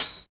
87 TAMB.wav